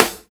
SNARE85.wav